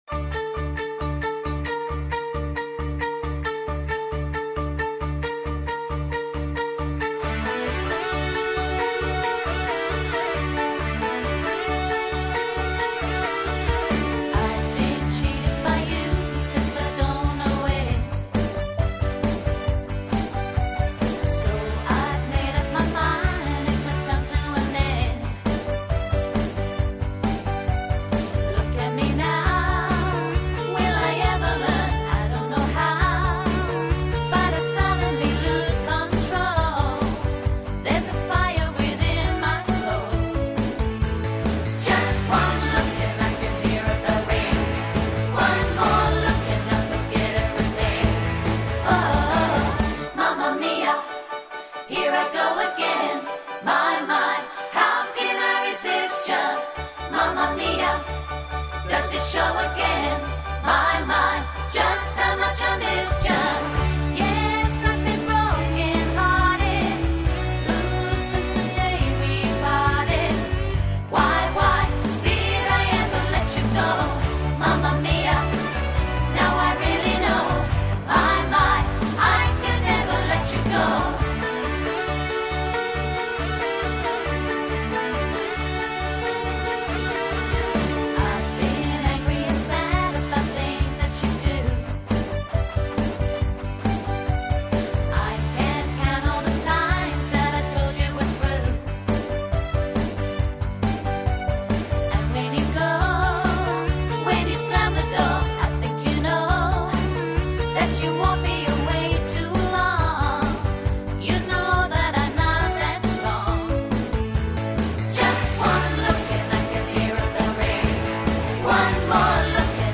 With tight harmonies and meticulous attention to detail